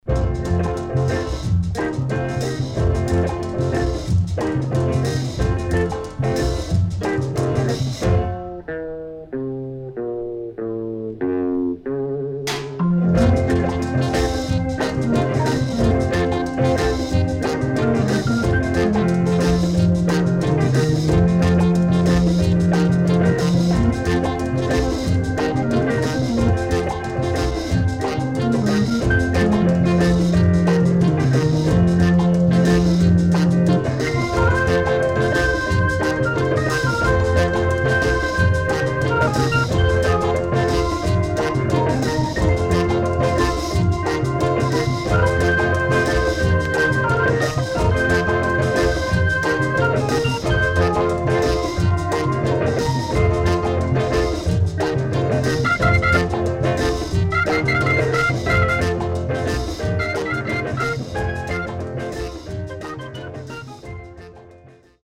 HOME > REISSUE [SKA / ROCKSTEADY]